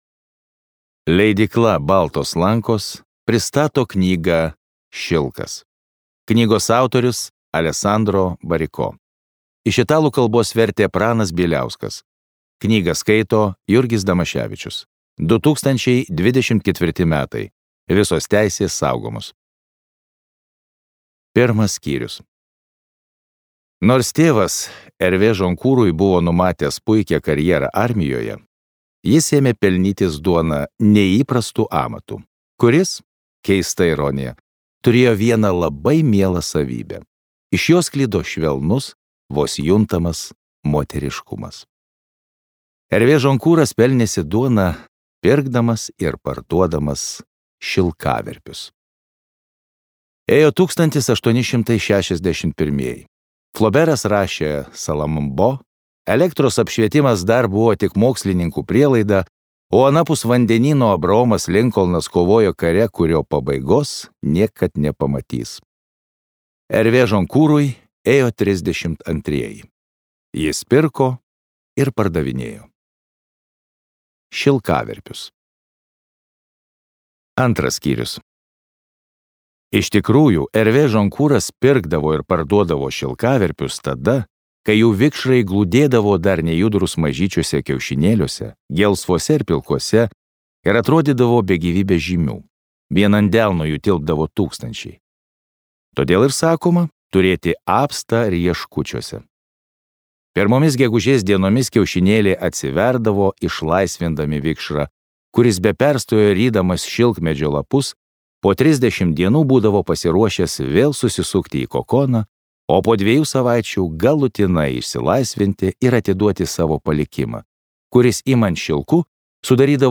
Šilkas | Audioknygos | baltos lankos